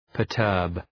Προφορά
{pɜ:r’tɜ:rb}